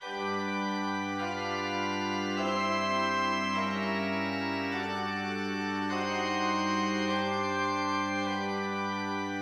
Pedal point example.
Pedalpoint.mid.mp3